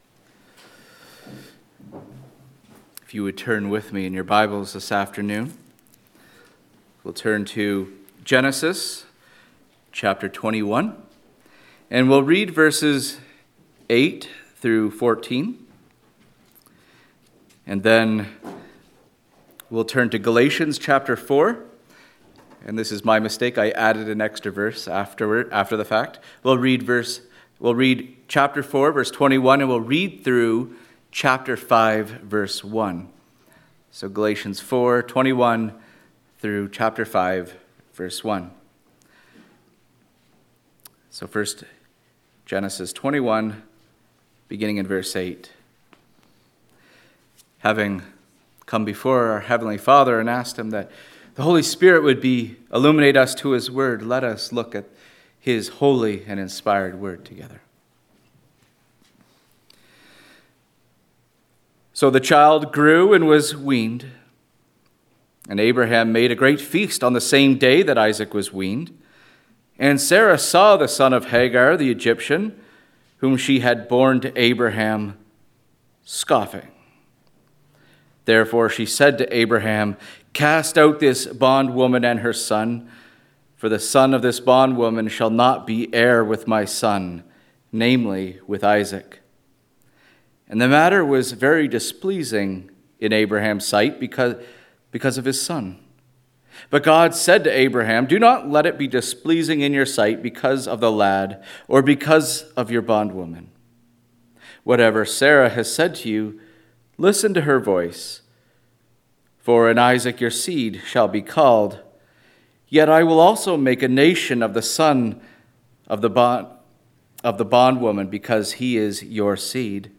4.Sermon-.mp3